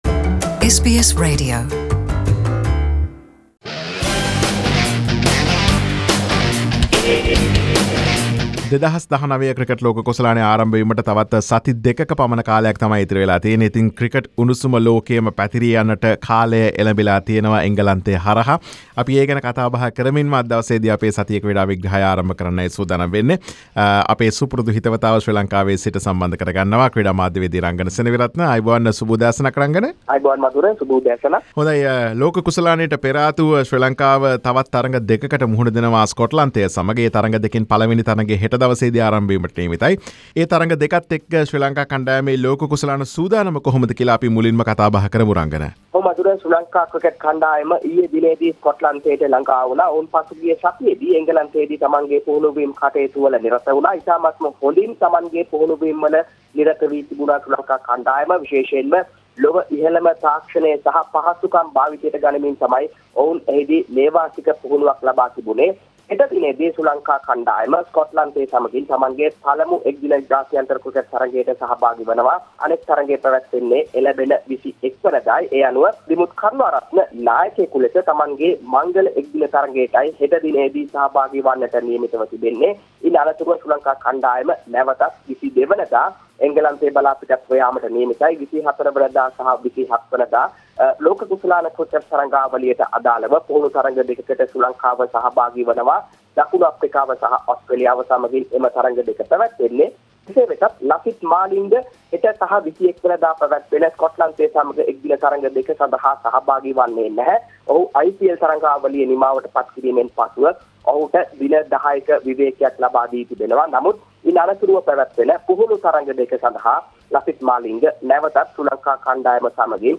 Today’s content: Sri Lanka Cricket Team Preparation for upcoming ICC Cricket World Cup 2019, ICC announced commentators for the Cricket World Cup, how Sri Lanka is going to contest with Scotland, Pakistan U19 tour of Sri Lanka and Sri Lanka Athletic squads for 2 International Athletic Championships. Sports journalist